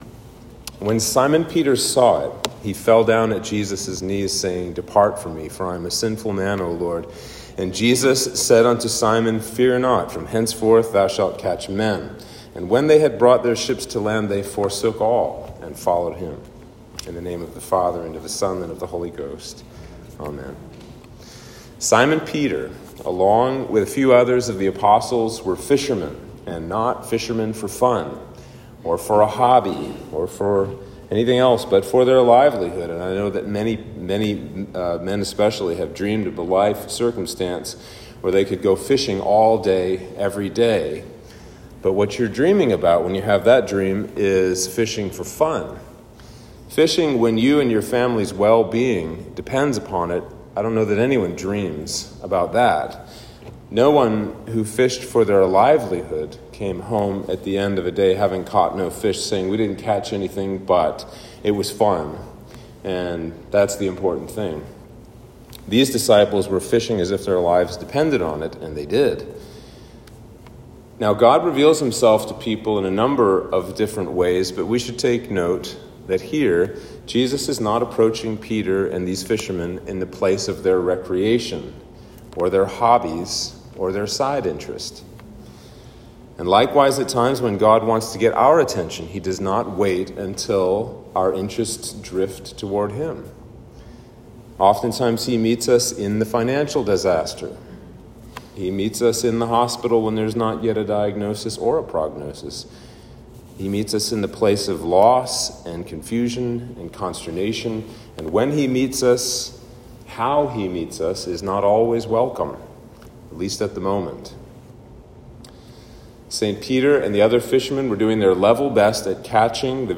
Sermon for Trinity 5